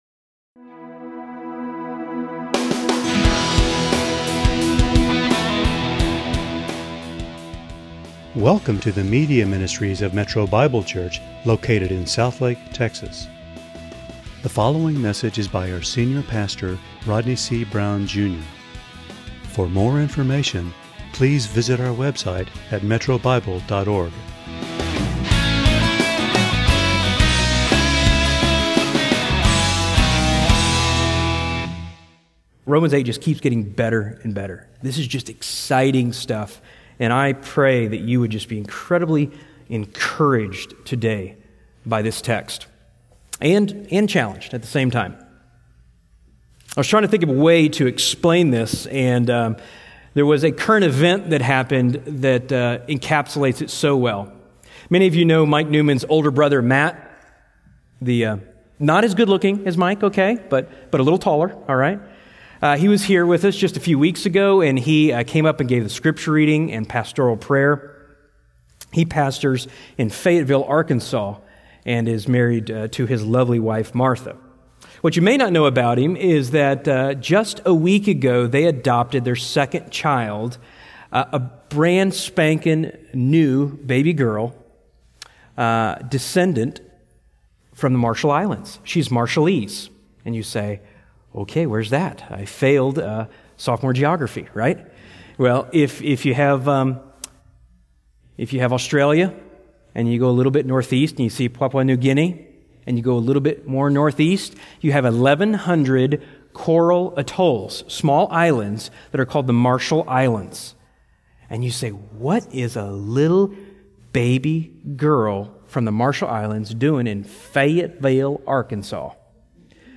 × Home About sermons Give Menu All Messages All Sermons By Book By Type By Series By Year By Book Adopted by God It is great comfort to know you are a child of the King.